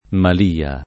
malia [ mal & a ] s. f.